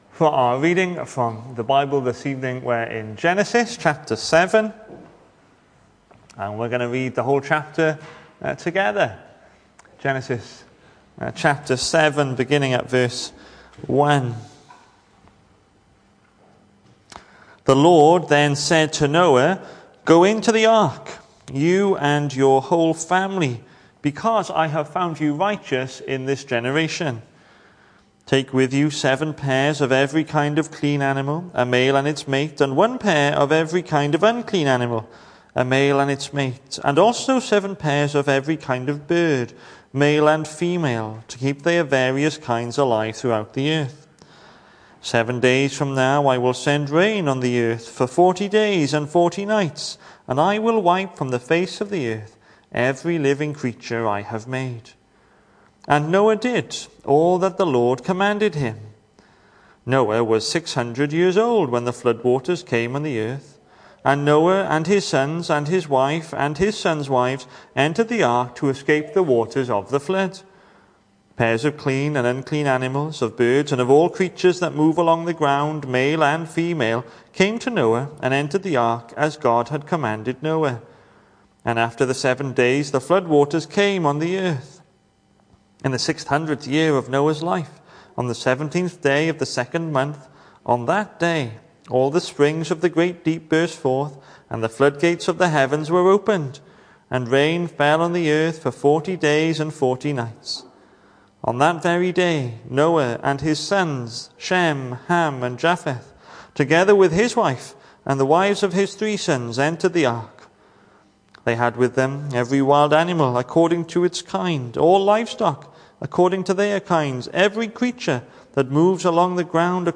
Weekly sermon recordings from an English speaking Evangelical church in West Street, Gorseinon, Swansea, SA4 4AA.